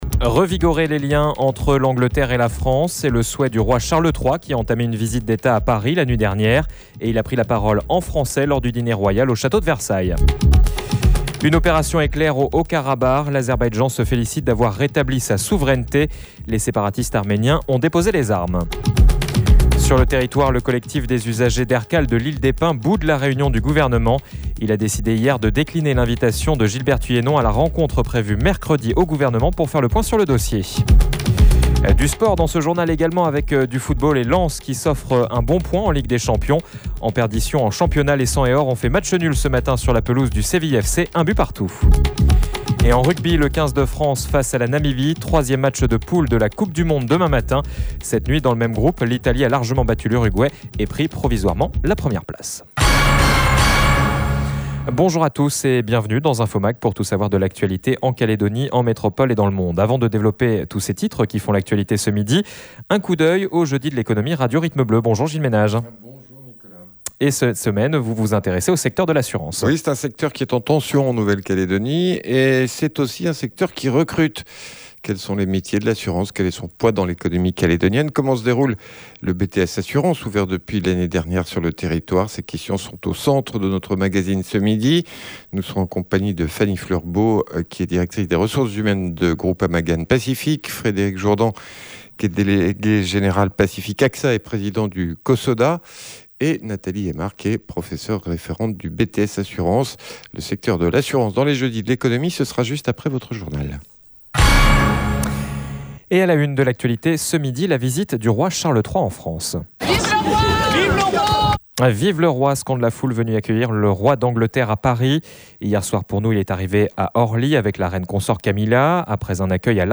JOURNAL : INFO MAG JEUDI